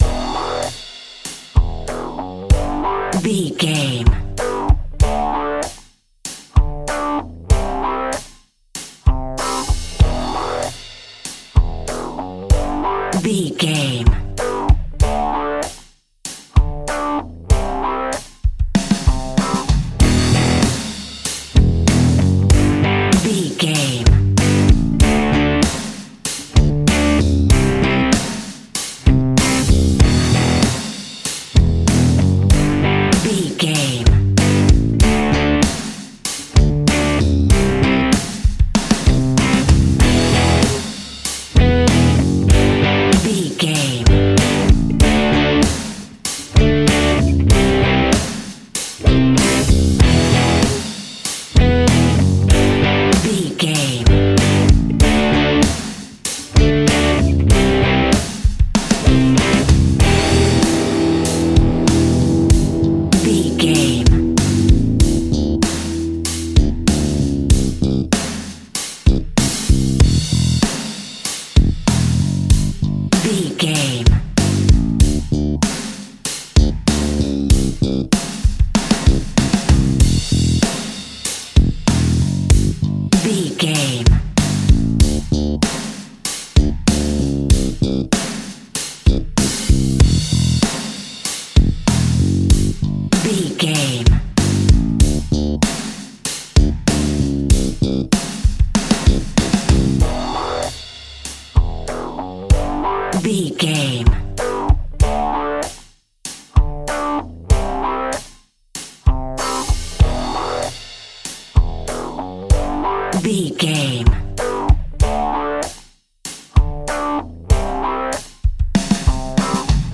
Aeolian/Minor
energetic
driving
heavy
aggressive
electric guitar
bass guitar
drums
hard rock
heavy metal
blues rock
distorted guitars
hammond organ